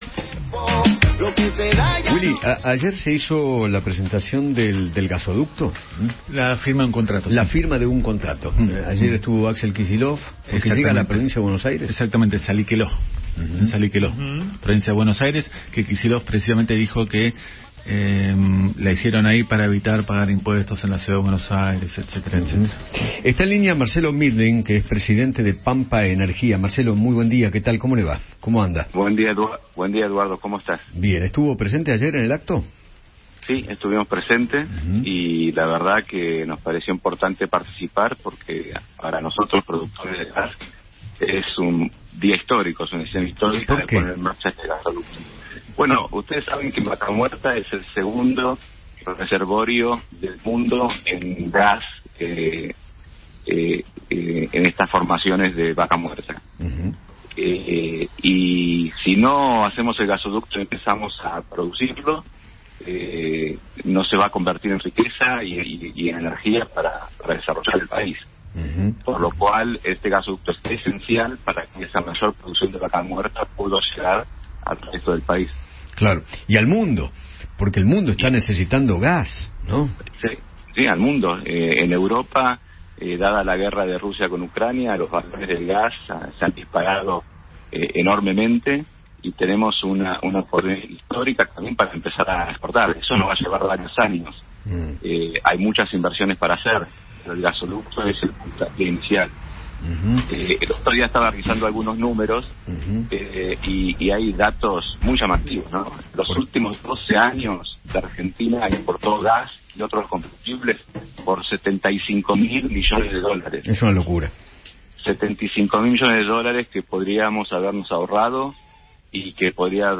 Marcelo Mindlin, presidente de Pampa Energía, habló con Eduardo Feinmann sobre el acto que presenció ayer en Saliqueló para dar comienzo a la primera fase de la construcción del gasoducto Néstor Kirchner.